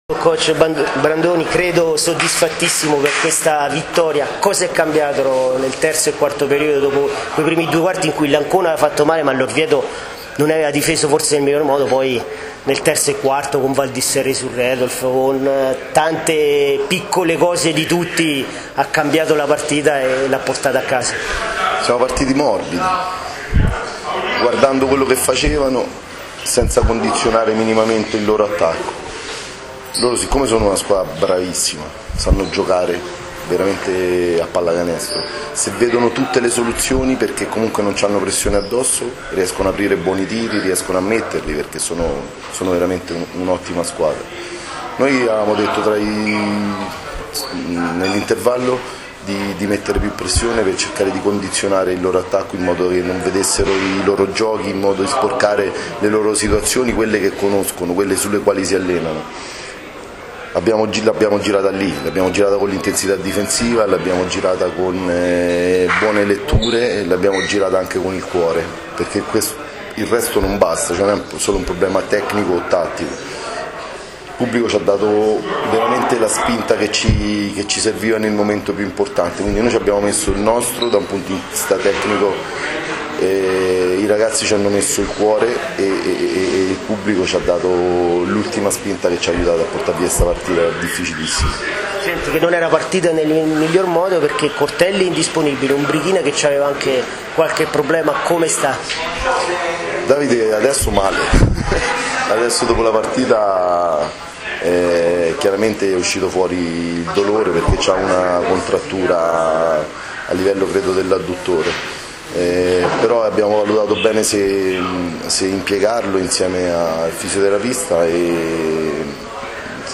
INTERVISTE del DOPO GARA